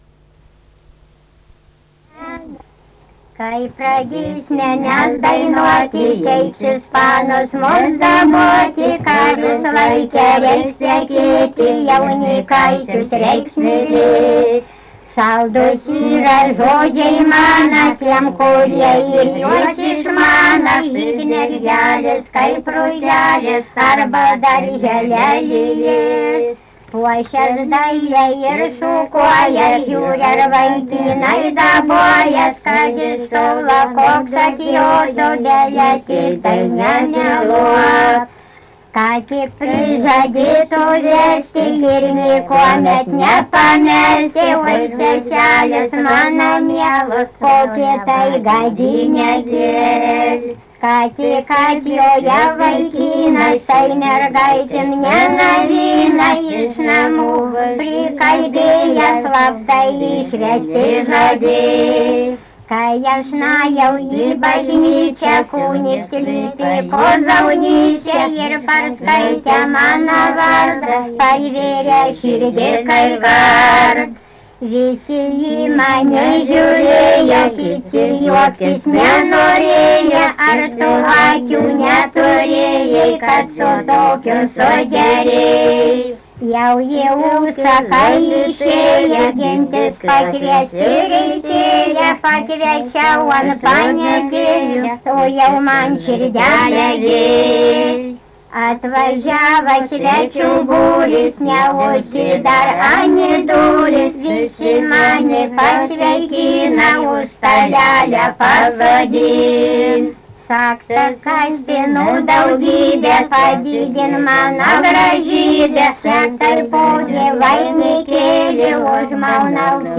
daina
Krakės
vokalinis